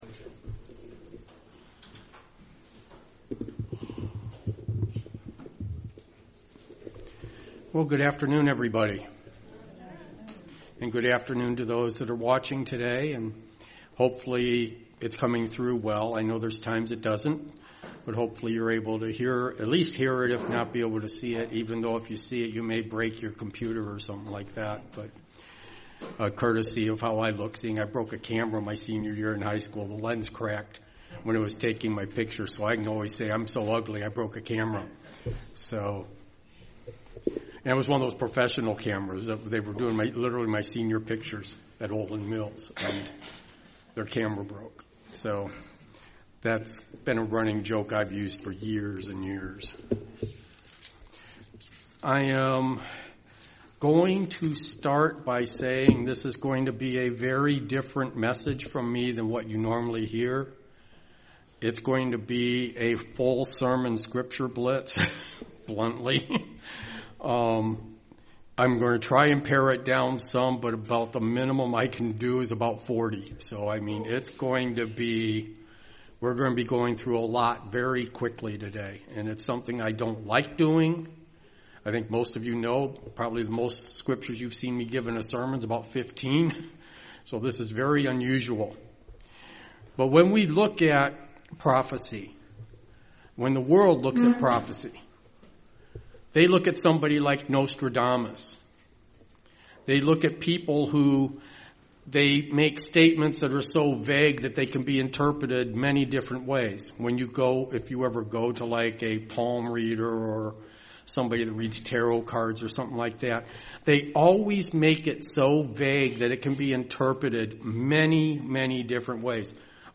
In this sermon we specifically follow a lot of the prophecy surrounding Christ's life and death.
Given in Dayton, OH